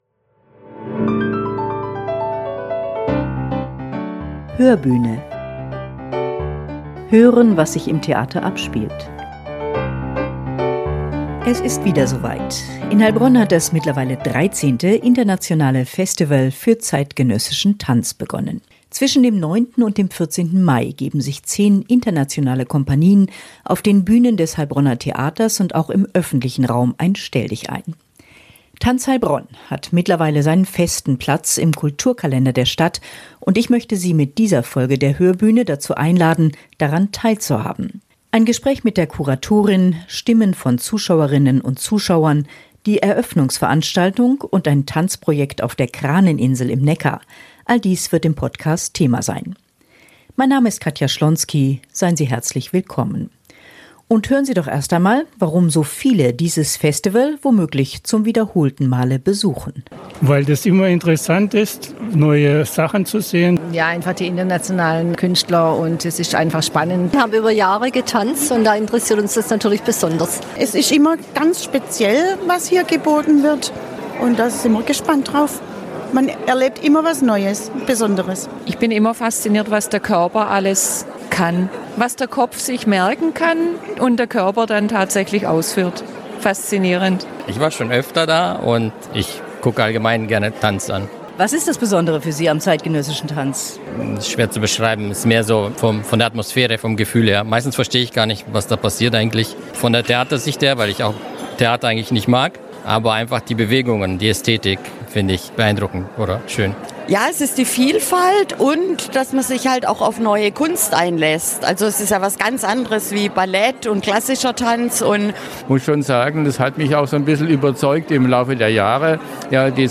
Außerdem gibt es einen Stimmungsbericht vom fulminanten Eröffnungsabend, einen kleinen Einblick in die Organisation dieses Events und einen Ausblick auf die weiteren Festival-Highlights.